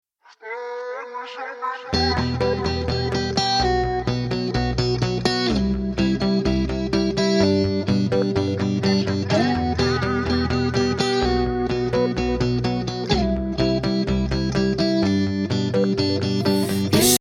Ищу звук гитары
Ребята не подскажите в какой библиотеки такие звуки гитар есть?или это готовые петли?